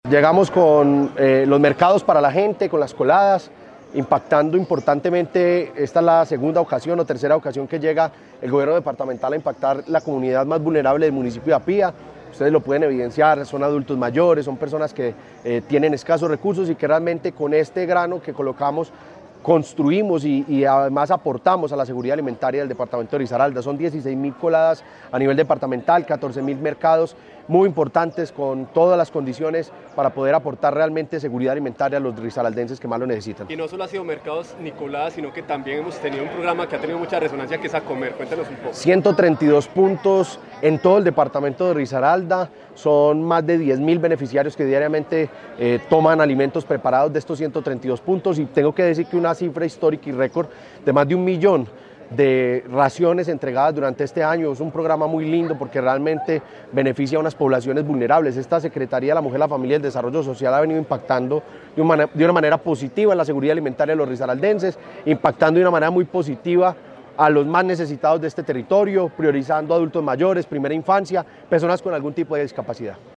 El Gobernador de Risaralda, Juan Diego Patiño, junto a su gabinete, llegó al municipio de Apía para reafirmar su compromiso con las comunidades más vulnerables.
En un ambiente lleno de gratitud y sonrisas, entregó personalmente las ayudas que fortalecen la seguridad alimentaria y dignifican la vida de quienes más lo necesitan.